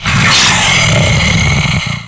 File:Dogadon's Hissy Growl.oga
Dogadon sound effect from Donkey Kong 64
Dogadon's_Hissy_Growl.oga.mp3